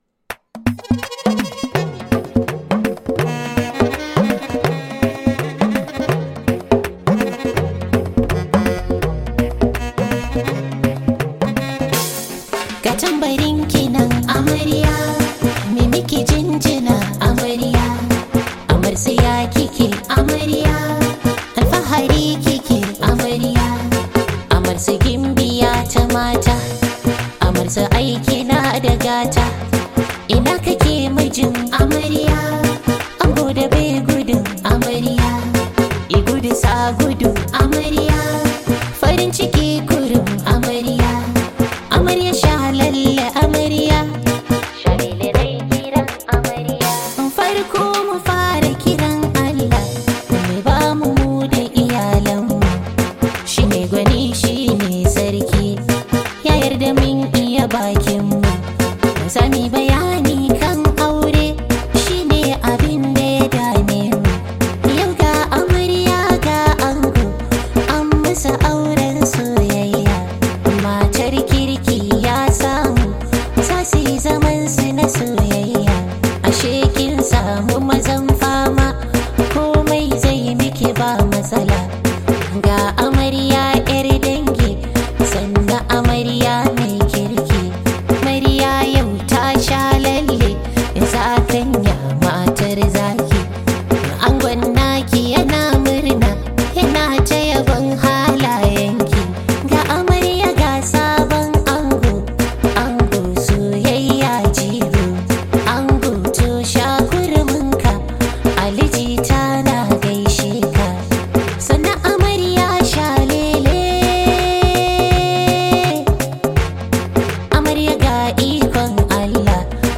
Hausa love song